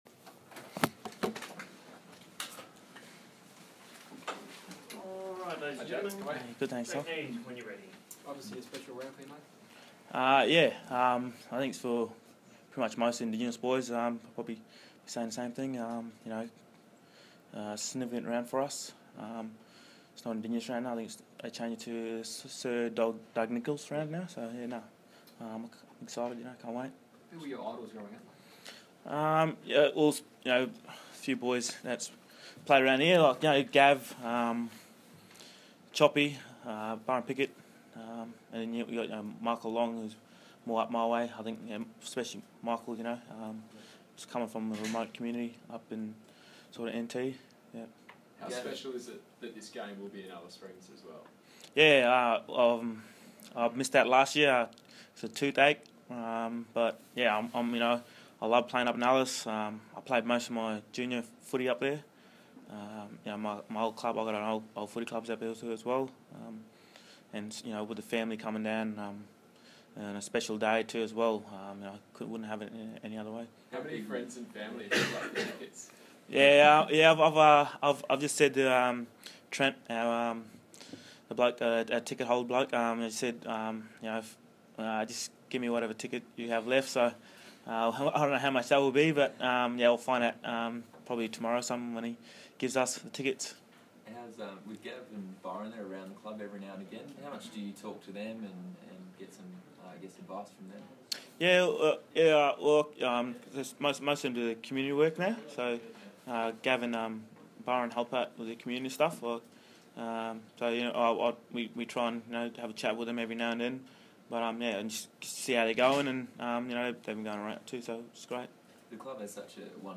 Jake Neade press conference - Wednesday, 25 May, 2016